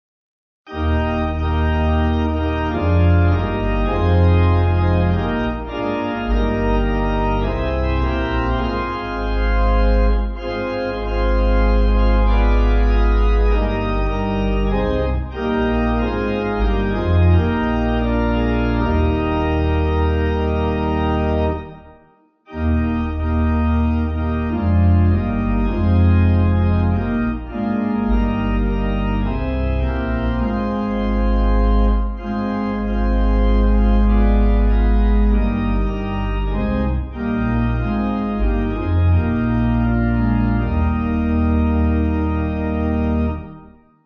Organ
(CM)   8/Eb